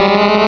pokeemerald / sound / direct_sound_samples / cries / smeargle.aif
-Replaced the Gen. 1 to 3 cries with BW2 rips.